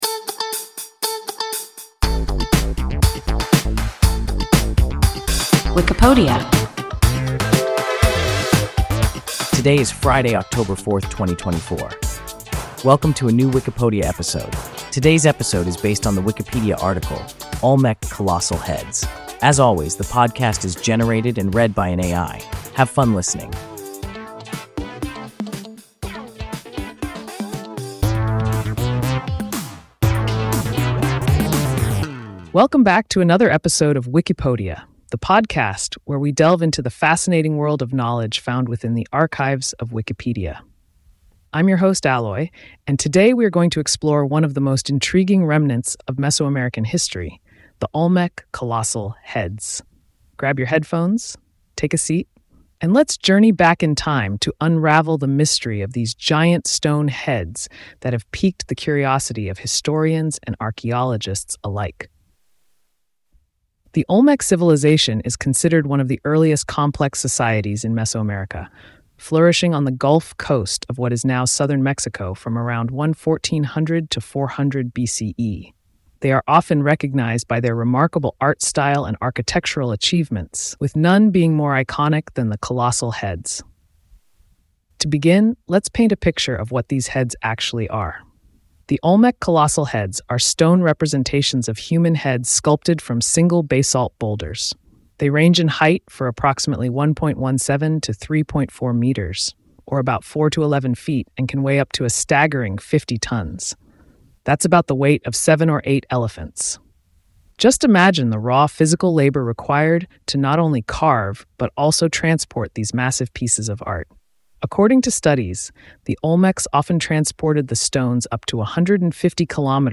Olmec colossal heads – WIKIPODIA – ein KI Podcast